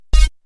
Play Beep Switch Sound - SoundBoardGuy
beep-switch-sound.mp3